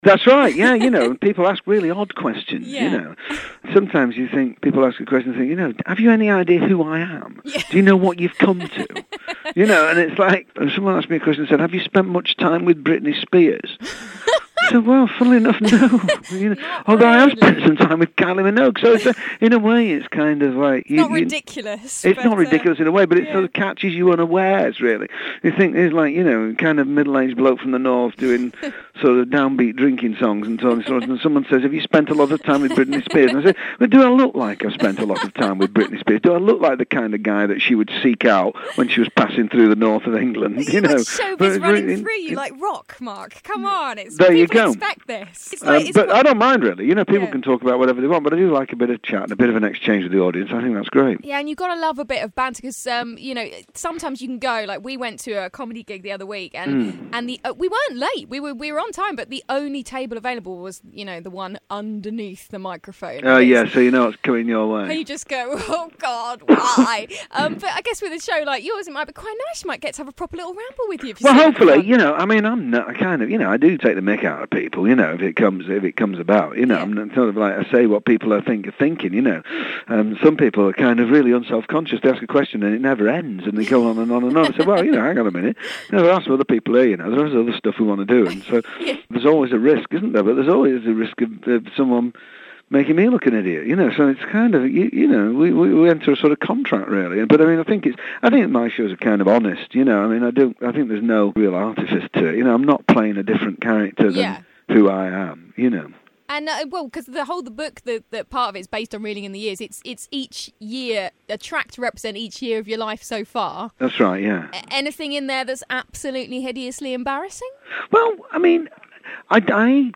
Glide's Morning Glory Interview with Mark Radcliffe Part 2